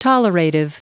Prononciation du mot tolerative en anglais (fichier audio)
Prononciation du mot : tolerative